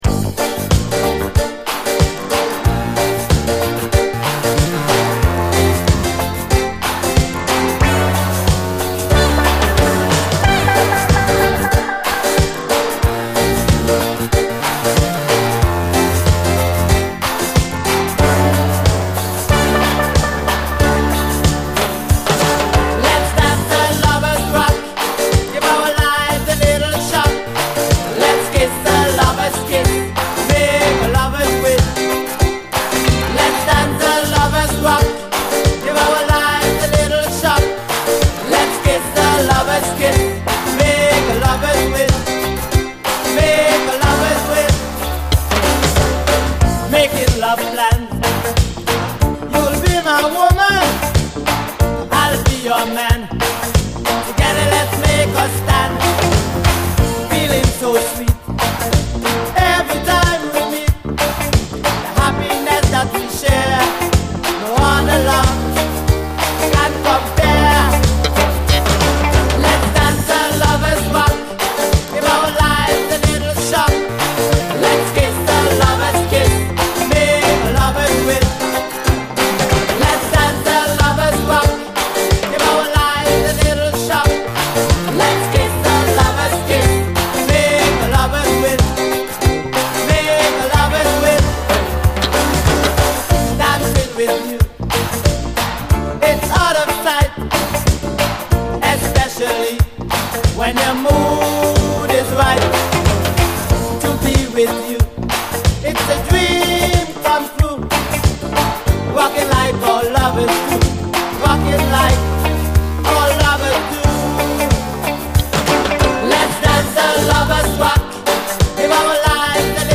DISCO, 80's～ ROCK, REGGAE, ROCK, FUNK-A-LATINA
しかしこれが奇跡的に最高なトロピカル・ダンサーに仕上がってるんです！